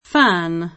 fän] s. m. e f.; pl. (ingl.) fans [fän@] — anche italianizz. come fan [fan], inv. — err. l’uso di fans come singolare — voce ingl. per «ammiratore, sostenitore, tifoso» e simili — solo con pn. ingl. la locuz. fan club [^n kl9^b]